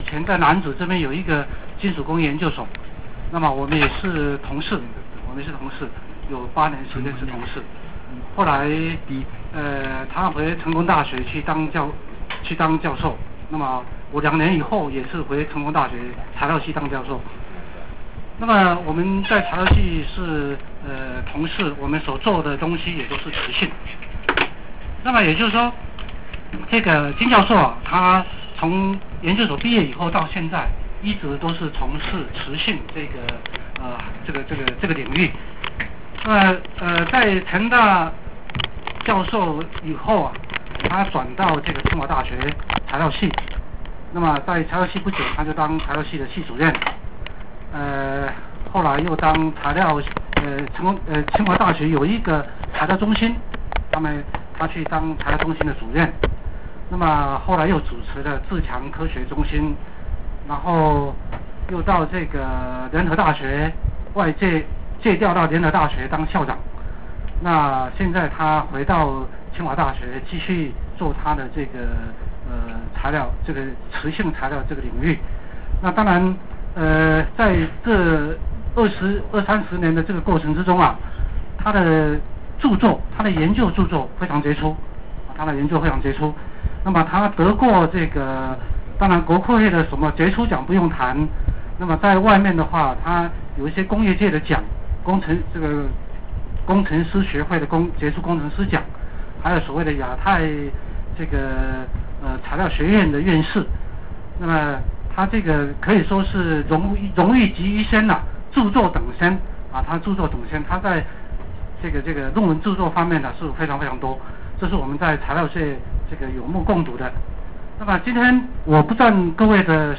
訪問稿 錄音檔 相 片 影 片 ● 紀錄稿 ● 淺談磁學 — 無所不在的 磁與人的關係 核磁共振的原理是什麼 ？